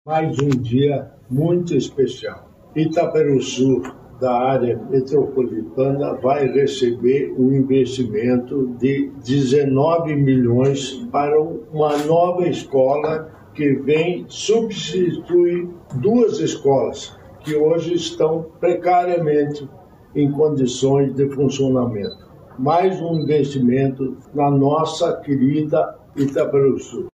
Sonora do governador em exercício Darci Piana sobre a construção da nova escola em Itaperuçu